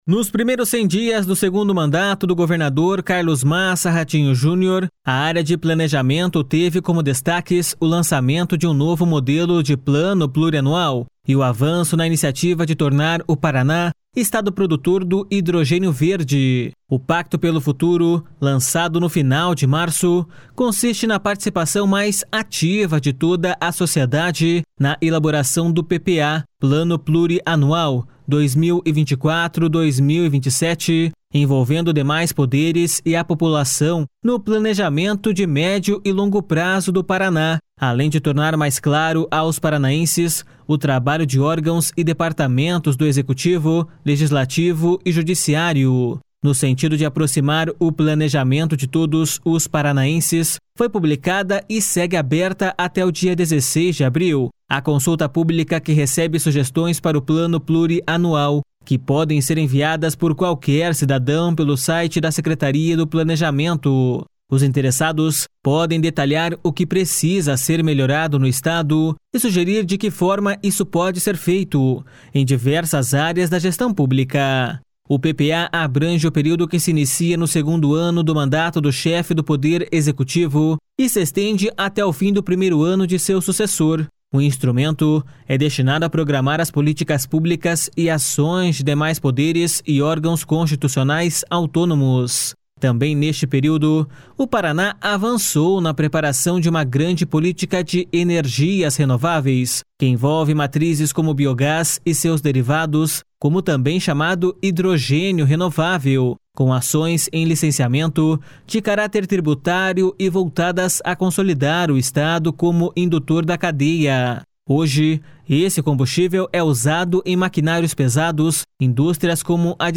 O secretário do Planejamento, Guto Silva, destaca a importância dessas tratativas para fazer do Paraná referência na área.// SONORA GUTO SILVA.//